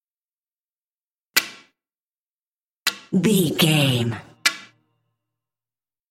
Billards fail hit
Sound Effects
hard